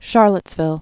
(shärləts-vĭl)